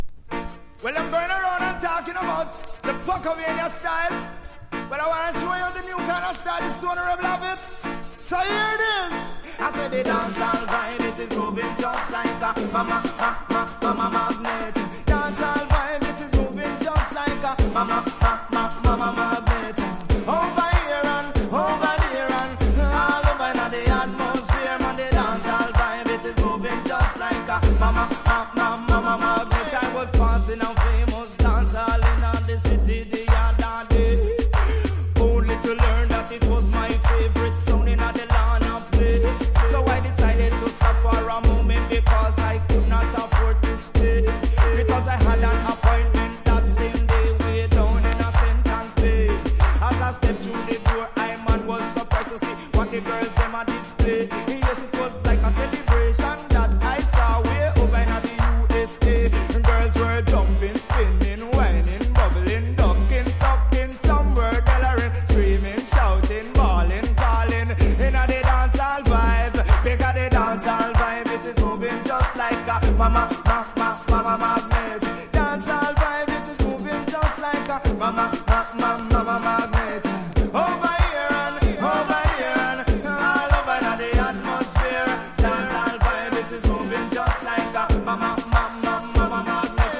• REGGAE-SKA